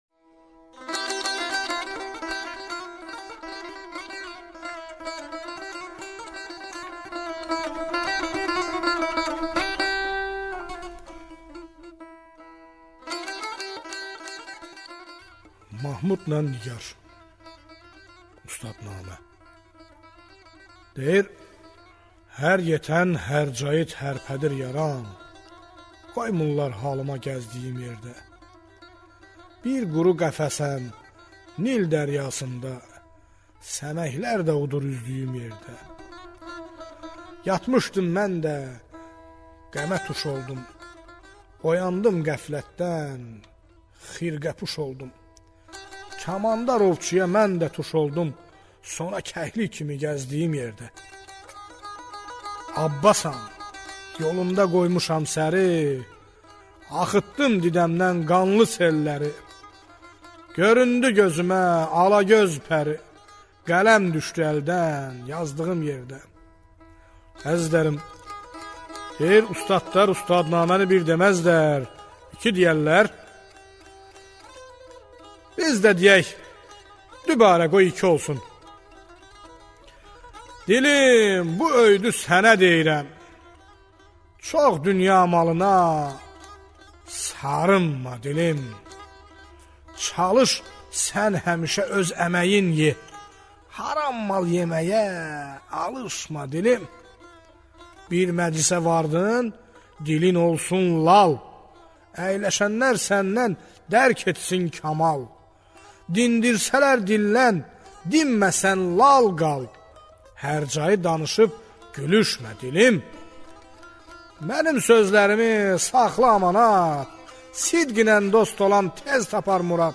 Azeri dastan